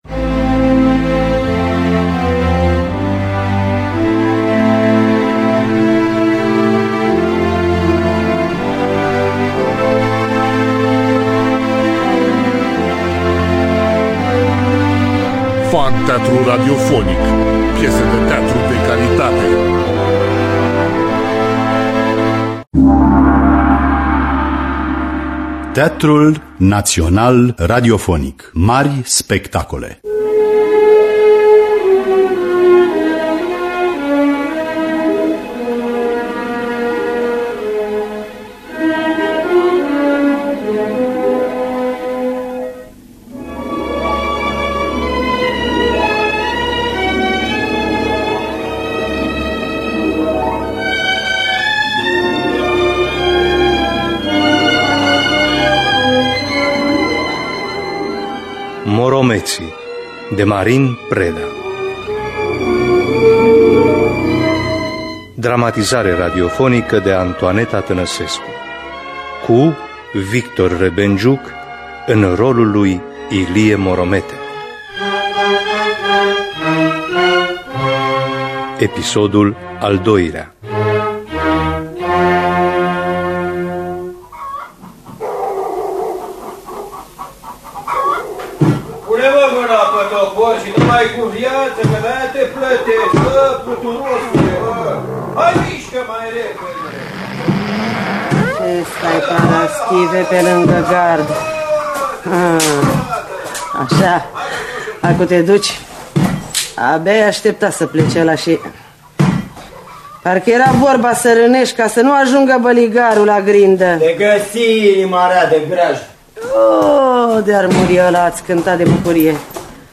Marin Preda – Morometii (1990) – Partea 2 – Teatru Radiofonic Online